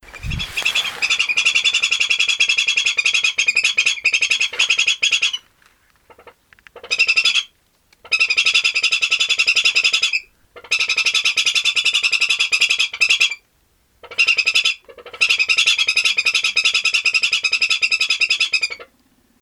Lugensa brevirostris brevirostris - Petrel plomizo
Petrel plomizo.wav